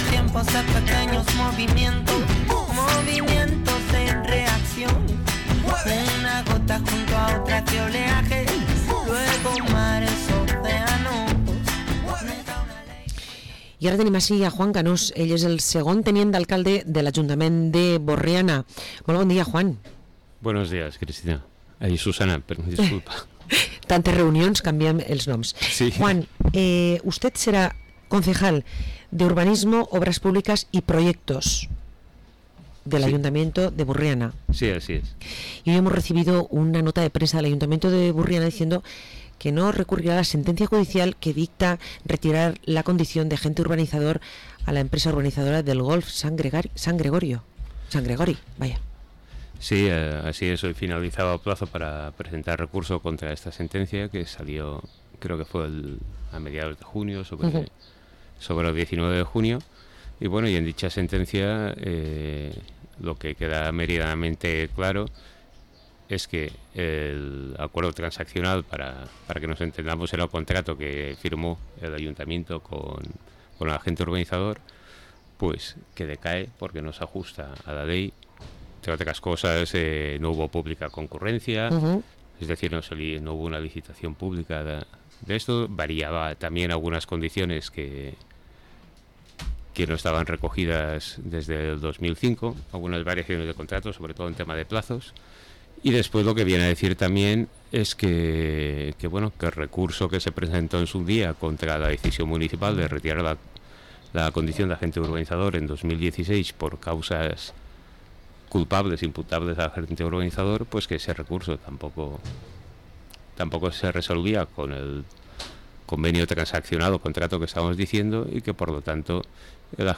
Entrevista a Juan Canós, segon tinent d’alcalde i regidor d’urbanisme a l’Ajuntament de Borriana